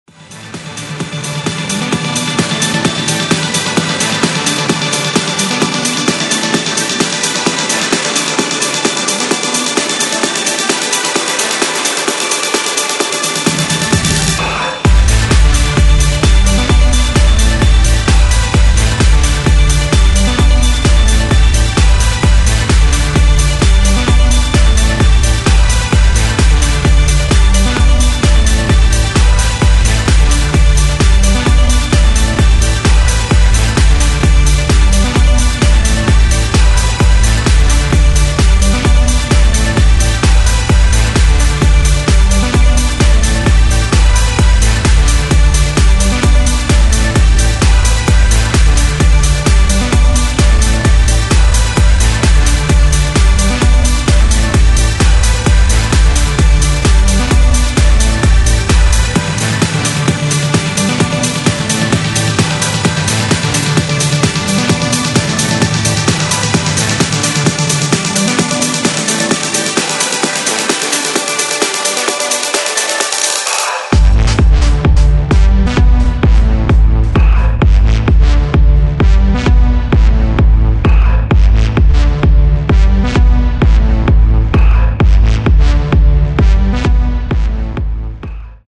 2021 Genre: Electronic Style: Techno